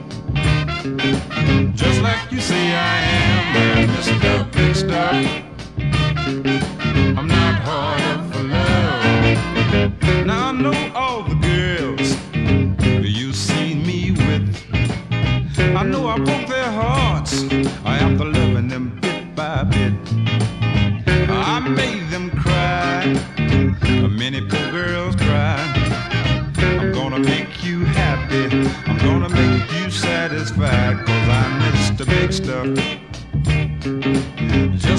Жанр: R&B / Соул / Фанк